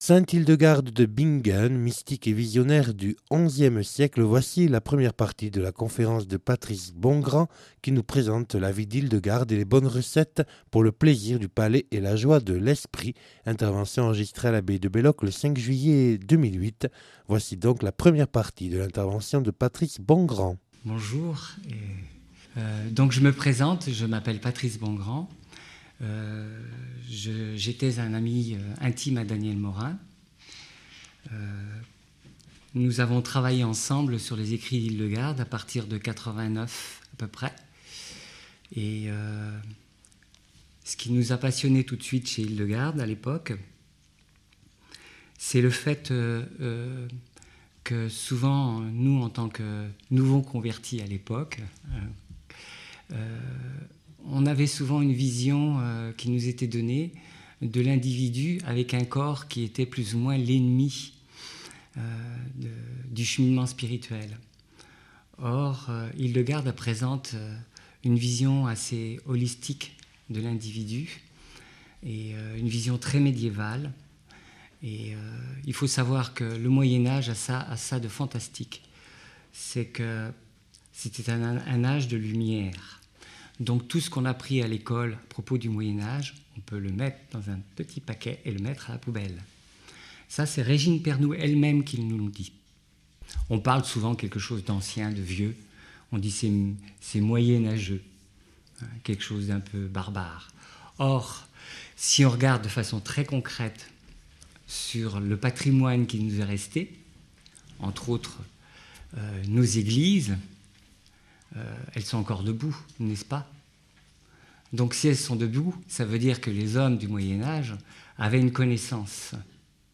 (Enregistrée le 05/07/2008 à l’abbaye de Belloc).